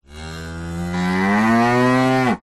Odgłosy zwierząt wiejskich
Krowa
audio_hero_s-cow-moo.mp3